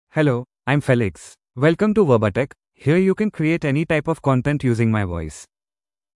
Felix — Male English (India) AI Voice | TTS, Voice Cloning & Video | Verbatik AI
Felix is a male AI voice for English (India).
Voice sample
Male
English (India)
Felix delivers clear pronunciation with authentic India English intonation, making your content sound professionally produced.